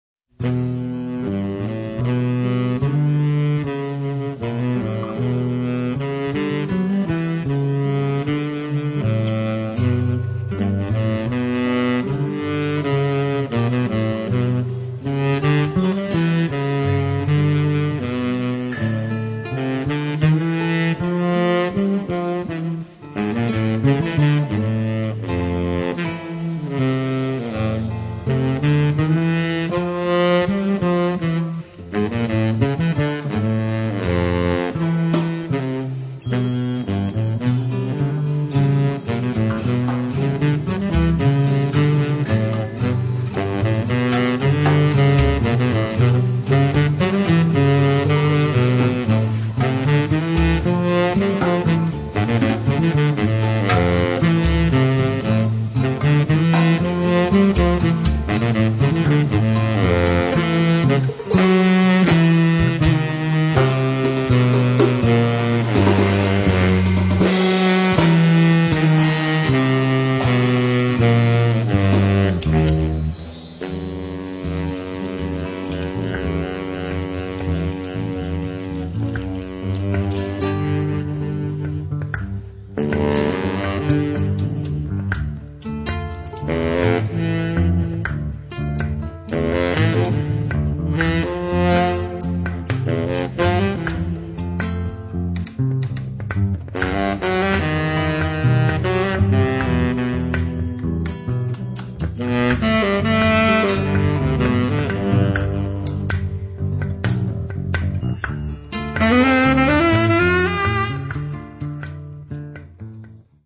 electroacoustic & electric bass, guitar
saxophones, bass clarinet, wooden flute
sitar, oud, guitars
percussions, tanpura
Registrato a Bari